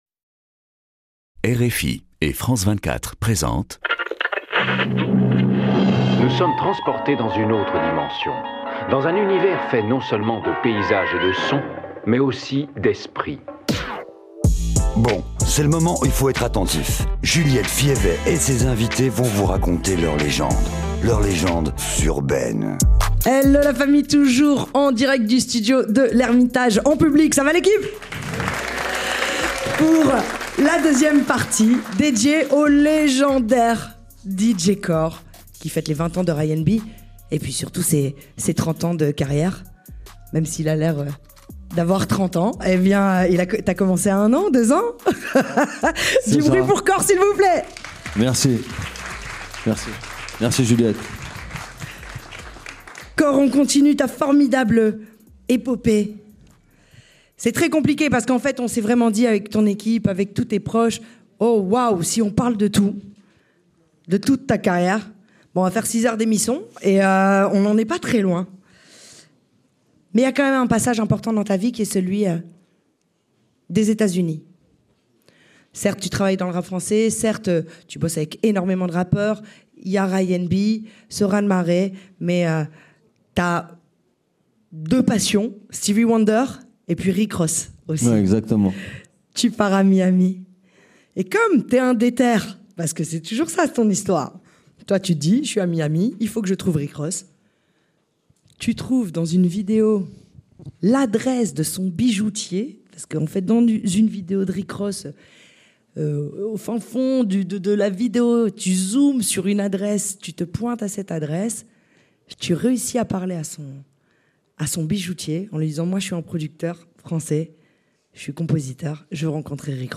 Au long de ces grands entretiens, artistes et professionnels (réalisateurs de clip, managers, tourneurs…), hommes et femmes de divers horizons et continents, s’expriment sur leurs parcours, sur l’actualité ou encore sur des